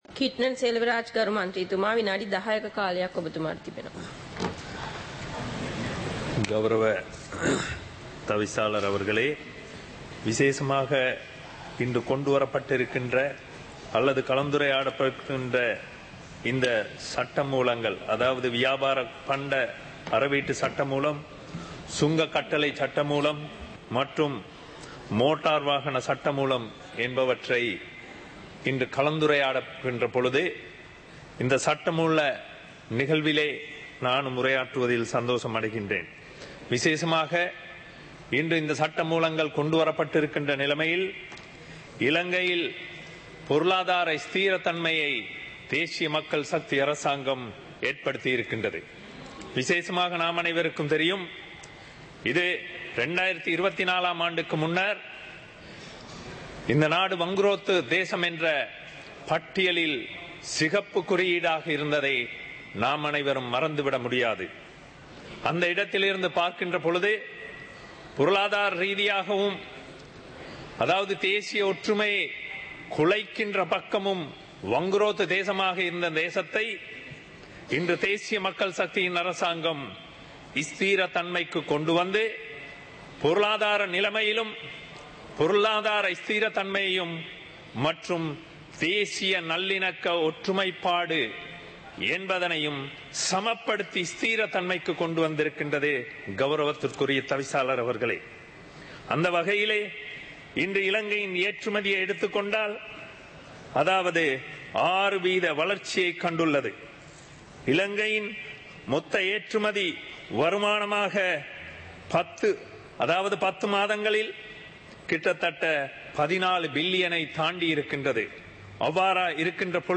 சபை நடவடிக்கைமுறை (2026-02-18)